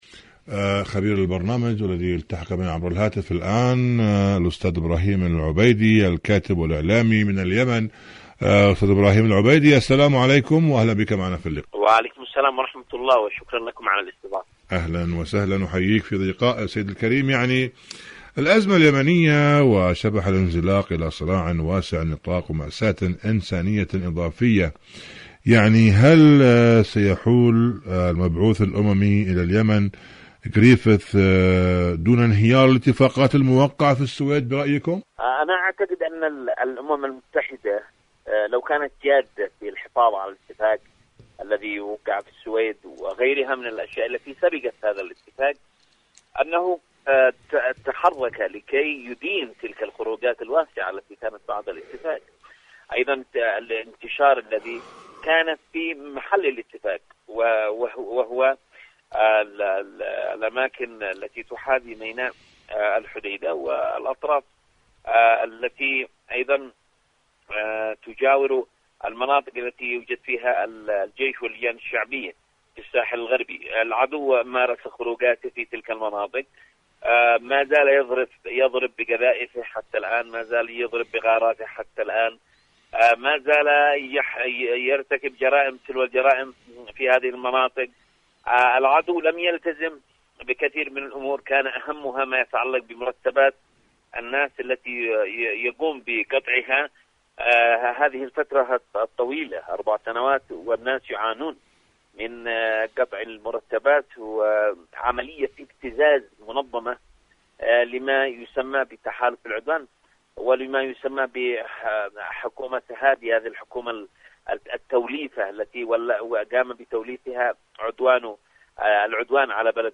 مقابلات مقابلات إذاعية برامج إذاعة طهران العربية برنامج اليمن التصدي والتحدي اليمن العدوان السعودي على اليمن صنعاء حركة انصار الله اتفاقات السويد شاركوا هذا الخبر مع أصدقائكم ذات صلة دور العلاج الطبيعي بعد العمليات الجراحية..